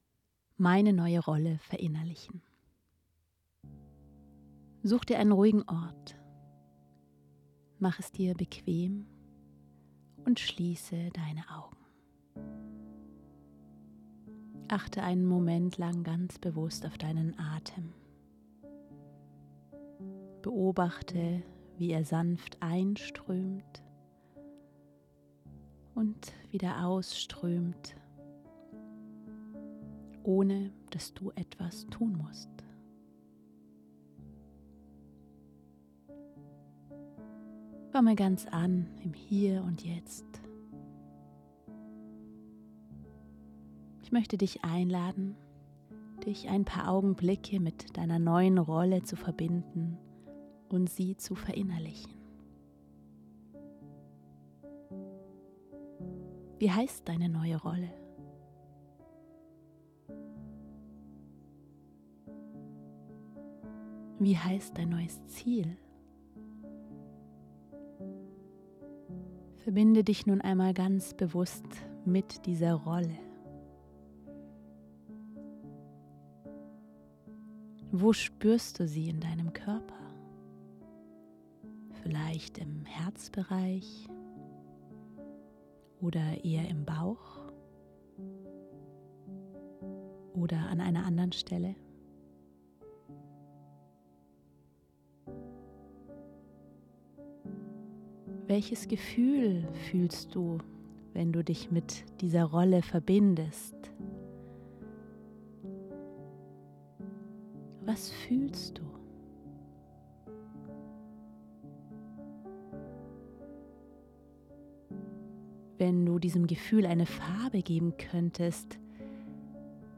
Gedankenreise: ROlle verinnerlichen